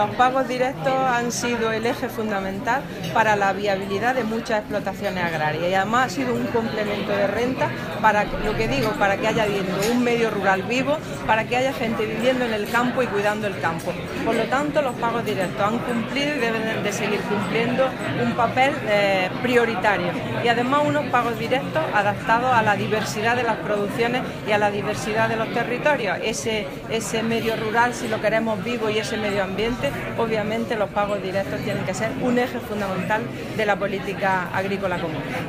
La consejera de Agricultura ha intervenido en Madrid en la conferencia que abre el debate sobre la reforma de la Política Agrícola Común post 2020
Declaraciones consejera pagos directos